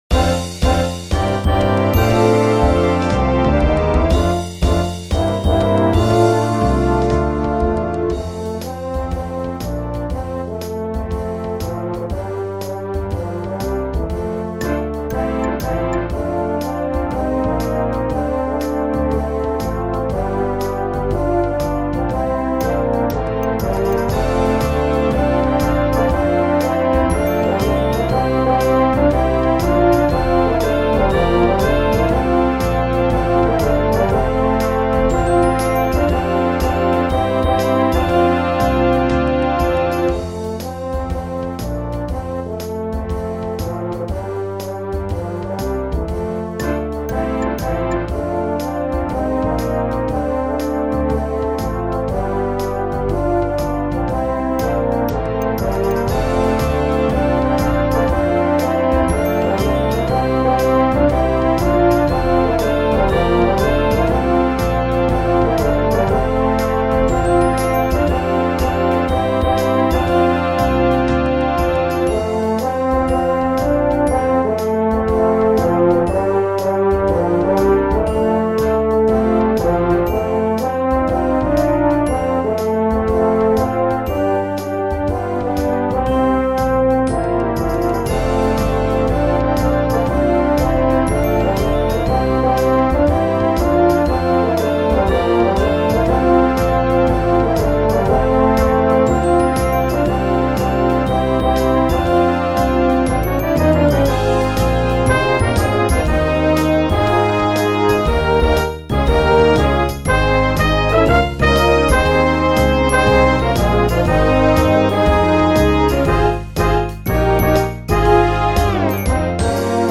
107B1 Brass Band $25.00
(computer generated sound sample)